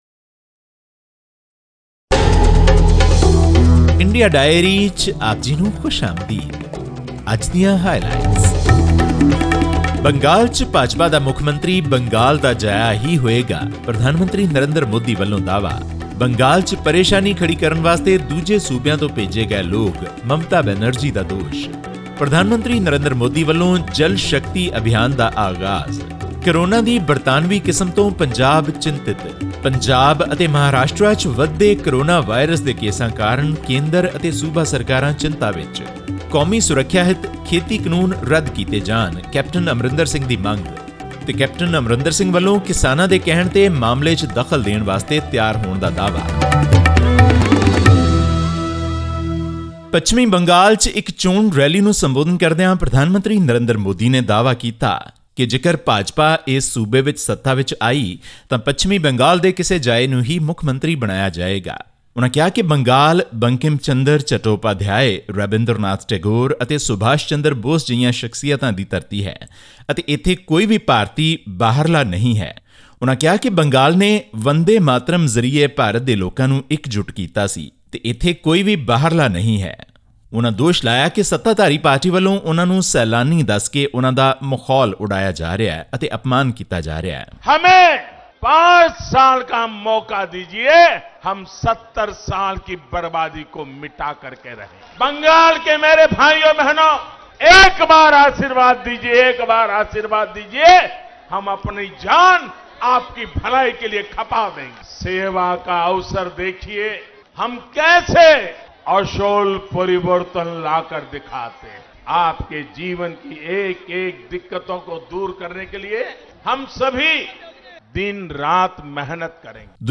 The recent spike in new COVID-19 cases in Maharashtra and Punjab is of grave concern, a health ministry official said on Wednesday during a briefing about the country's pandemic preparedness. This and more in our weekly news segment from India.